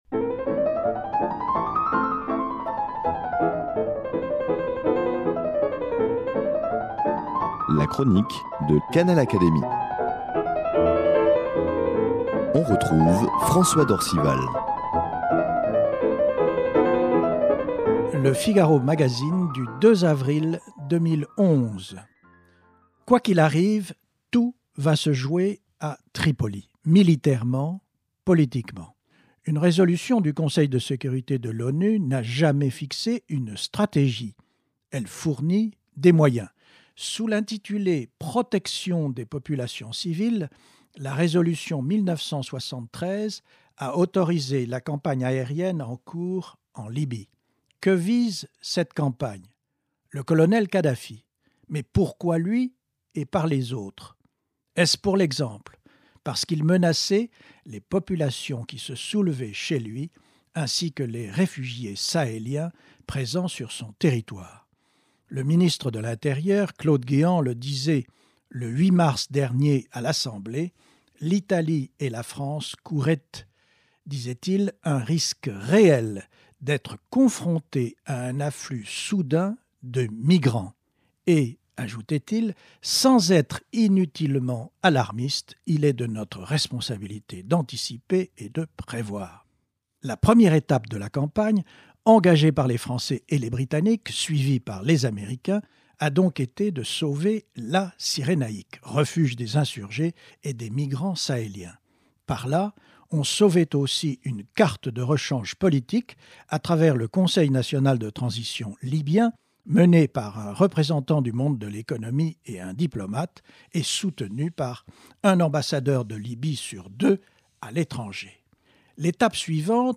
Où en sont les combats à Tripoli ? Quand le colonel Kadhafi va t-il rendre les armes ? L’académicien journaliste François d’Orcival, éclaire la situation au micro de Canal Académie.